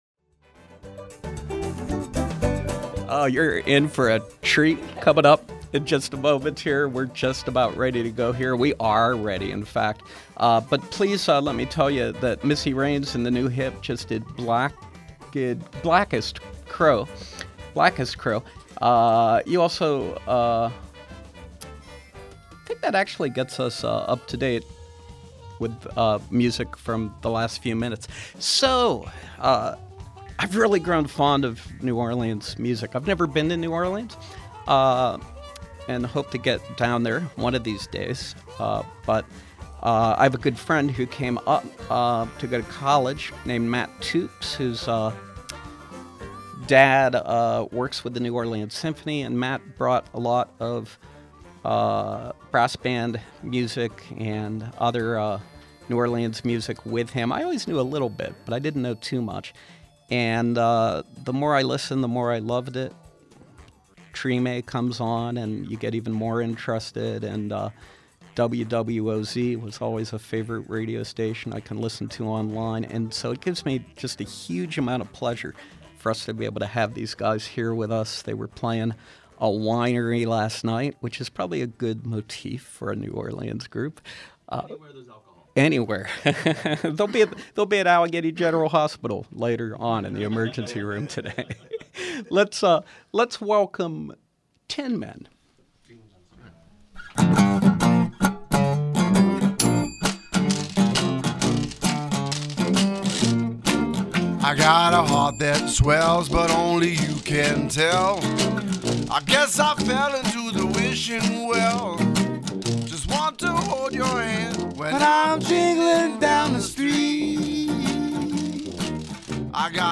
Live music with sousaphone, washboard and guitar trio